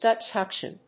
/dətʃ ɑkʃɪn(米国英語), ˌdʌtʃ ˈɔːk.ʃən(英国英語)/
アクセントDútch áuction